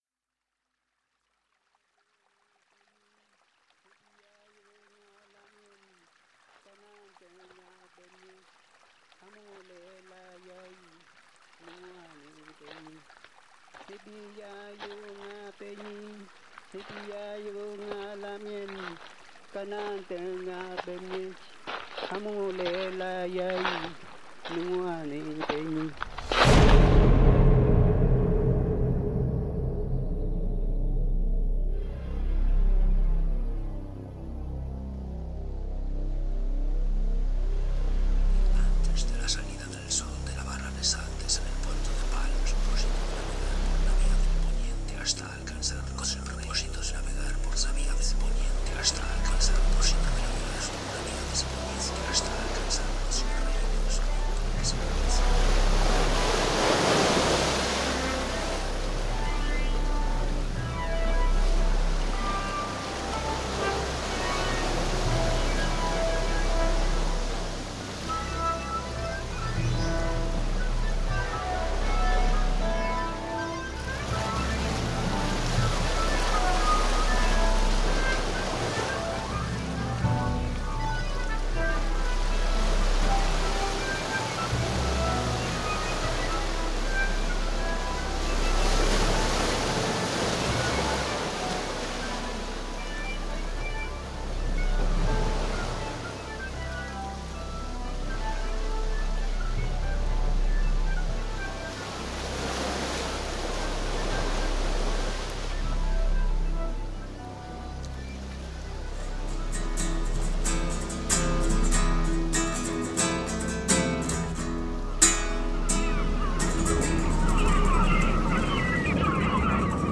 Kull Kull: cow or ox horn, wind instrument
Kultrúm: Ceremonial drum
Soundscape Series